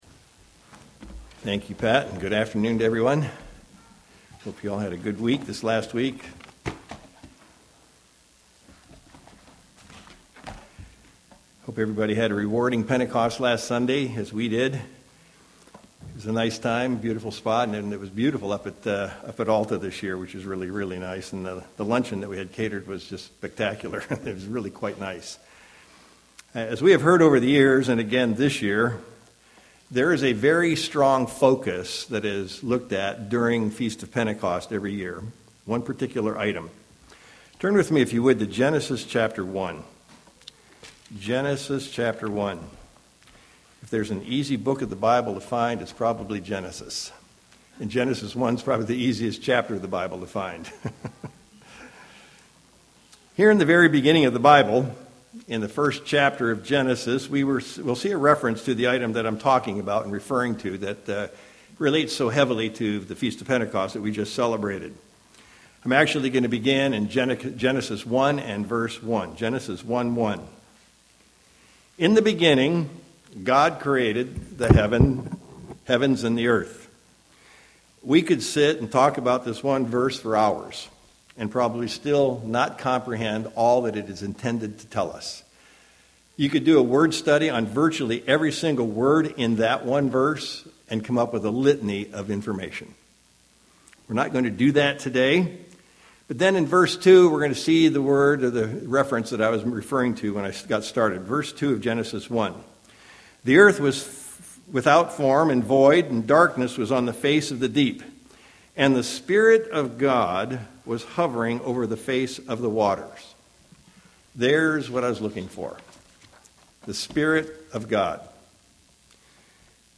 The Holy Spirit was at the epicenter of the Feast of Pentecost in 31 A.D. Since that is the case - it would be good to better understand what the Holy Spirit actually is. We'll explore that in this sermon.
Given in Sacramento, CA